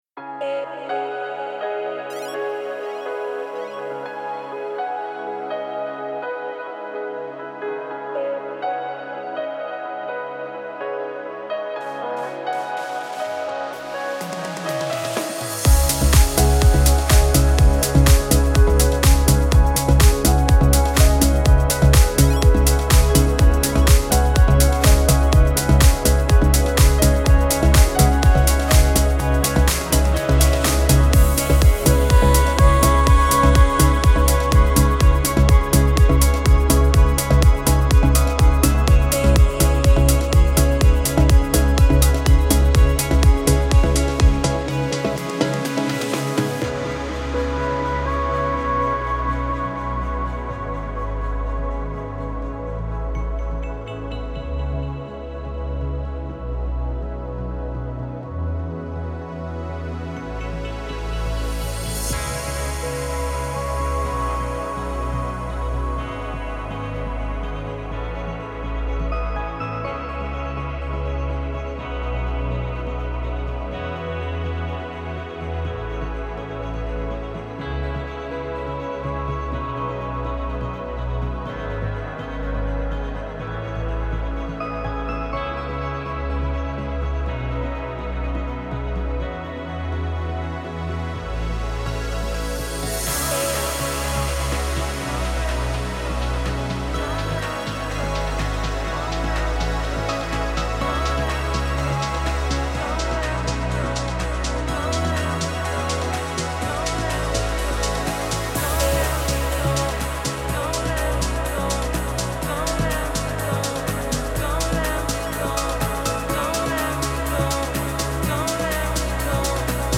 پر‌انرژی , رقص , موسیقی بی کلام